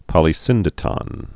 (pŏlē-sĭndĭ-tŏn)